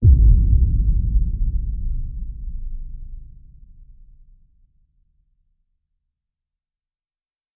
CinematicBoomNorm
Perfect for big, boom, cinematic.
big boom cinematic dark explosion large rumble sound effect free sound royalty free Movies & TV